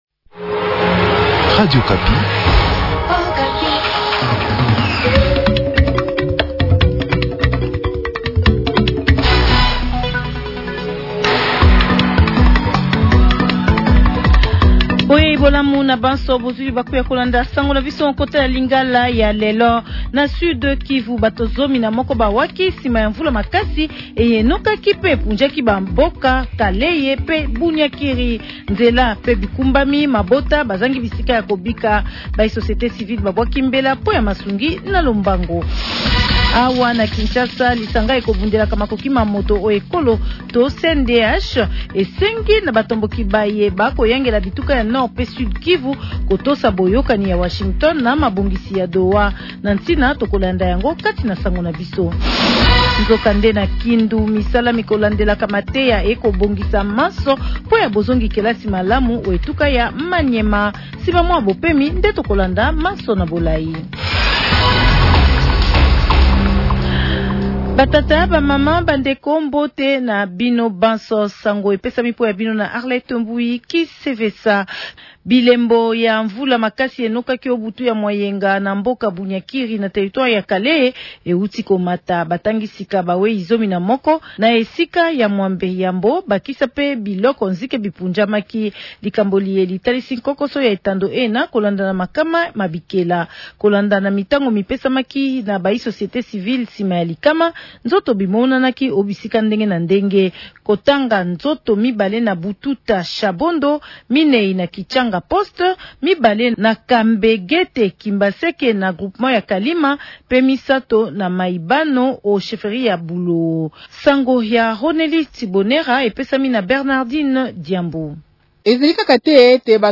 Journal Lingala Soir